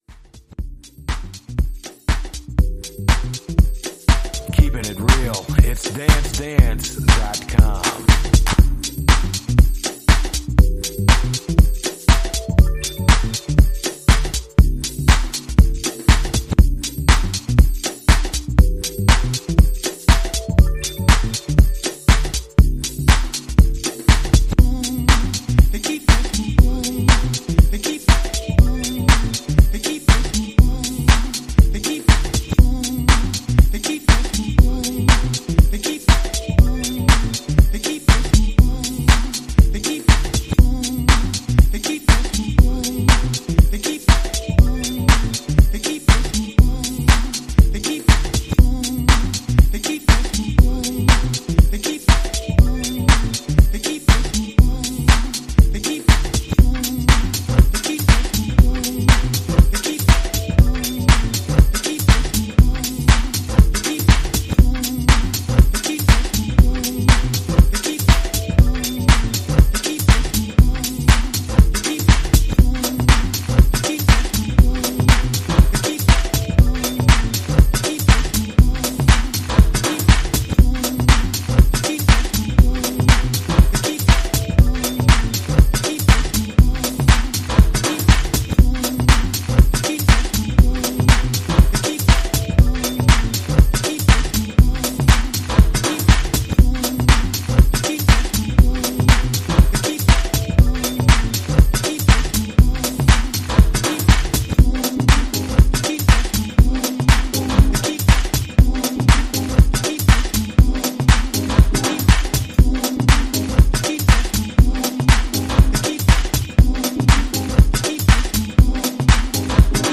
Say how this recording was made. using Traktor DJ here in Studio West